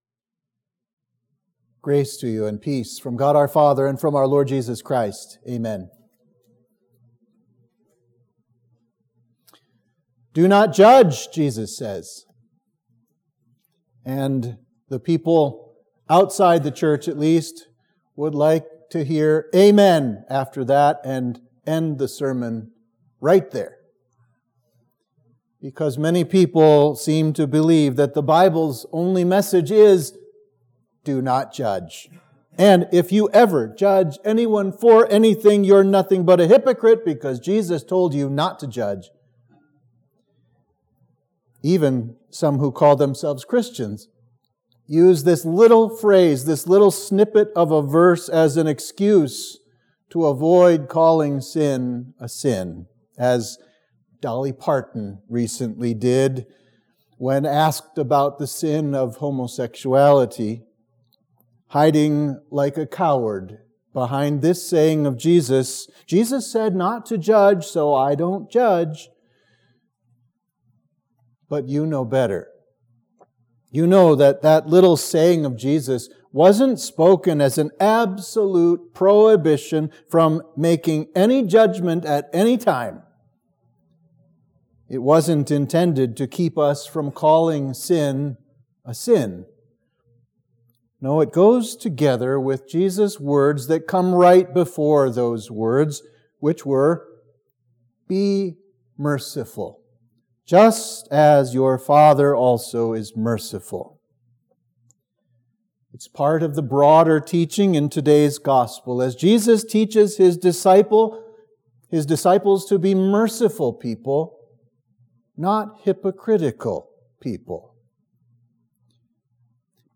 Sermon for Trinity 4